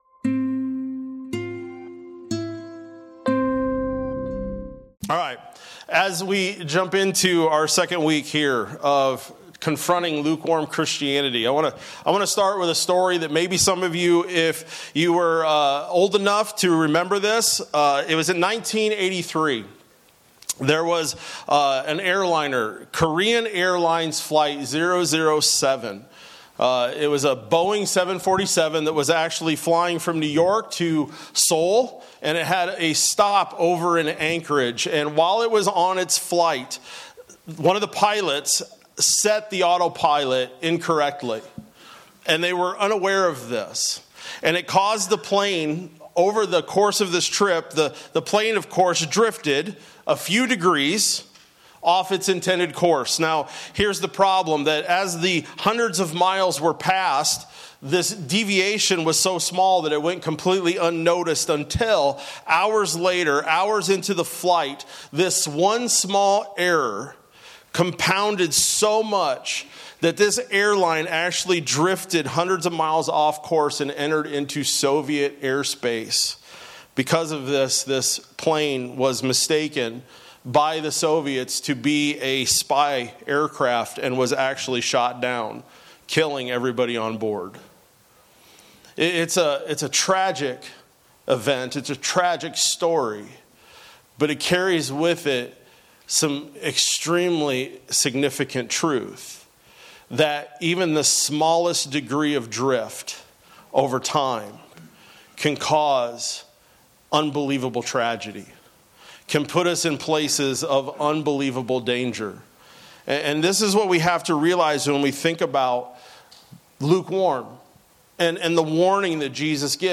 April-19-26-Sermon-Audio.mp3